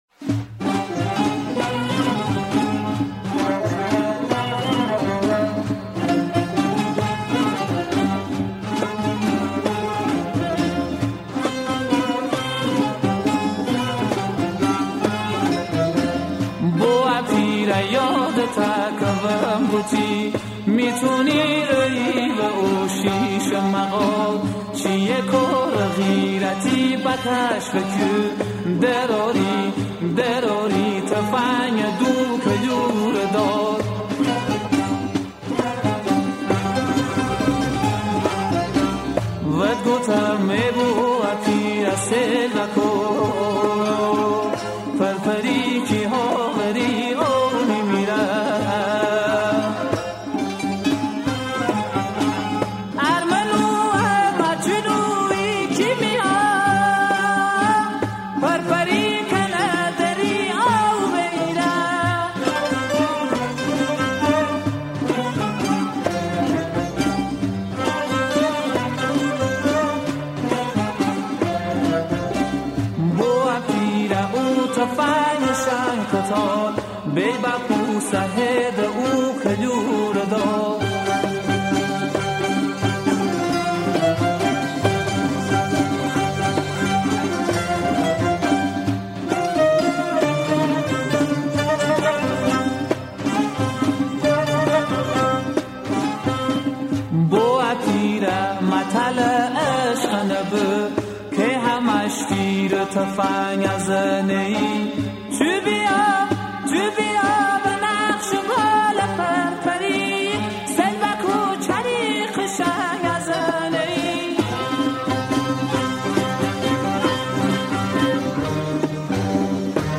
سبک: سنتی – گویش: مینجایی